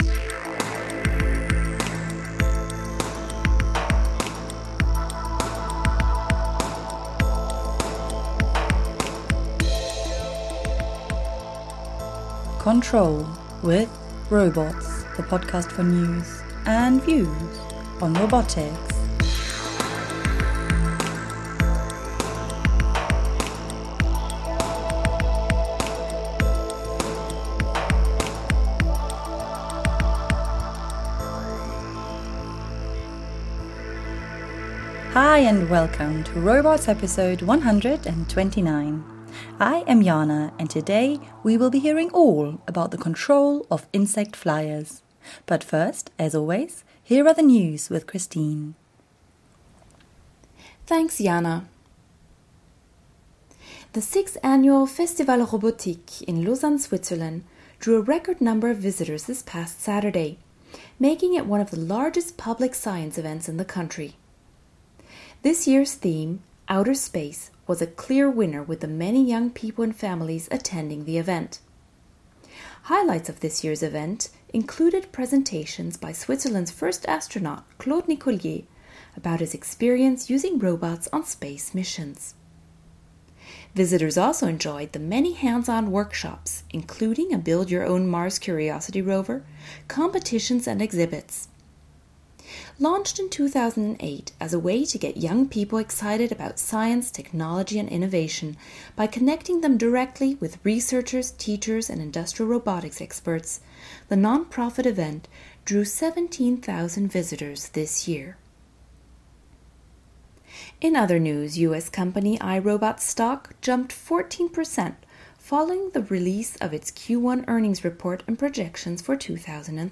Audio interviews about the controlled flight of insect robots - Robohub
In this episode we hear from researchers at the Harvard Microrobotics Lab about the Science paper published today reporting on the first controlled flight of an insect-sized robot.